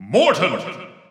The announcer saying Morton's name in English and Japanese releases of Super Smash Bros. 4 and Super Smash Bros. Ultimate.
Morton_English_Announcer_SSB4-SSBU.wav